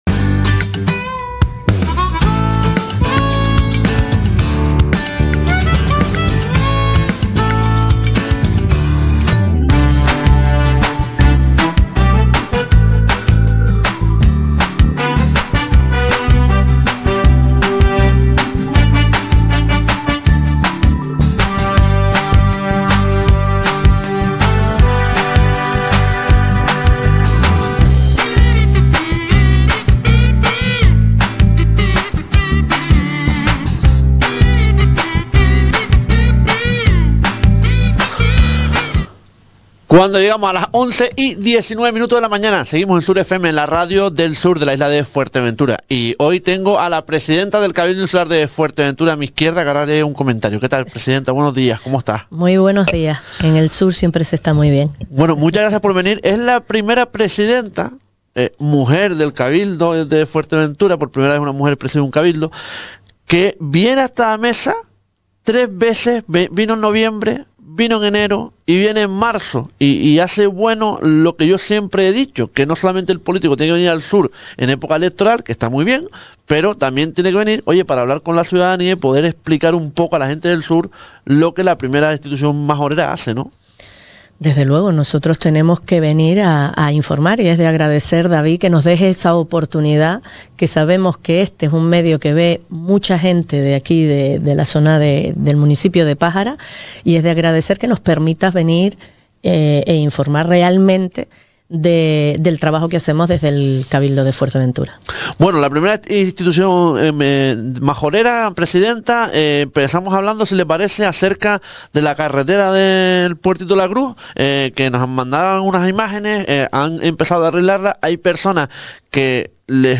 Entrevista a Lola García 13/03/25
Entrevistamos al la presidenta del Cabildo Insular de Fuerteventura, Lola García.